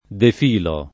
defilare v.; defilo [ def & lo ]